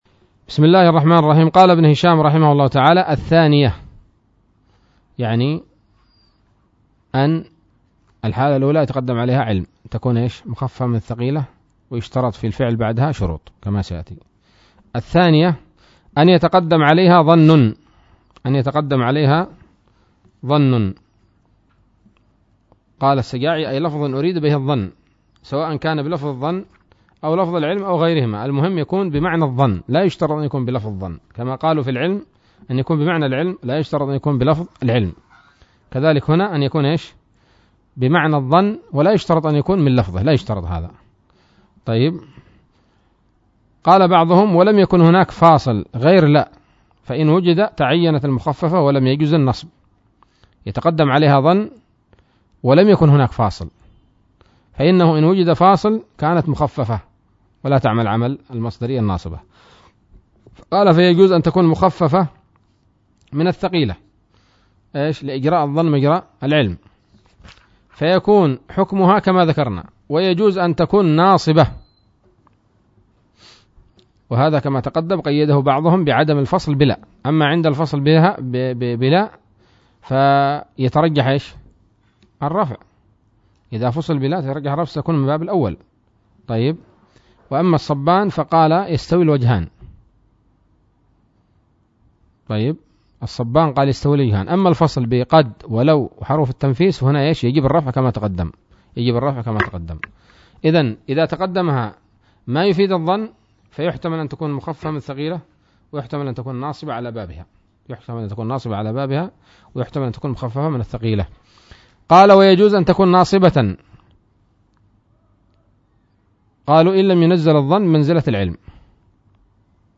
الدرس الحادي والثلاثون من شرح قطر الندى وبل الصدى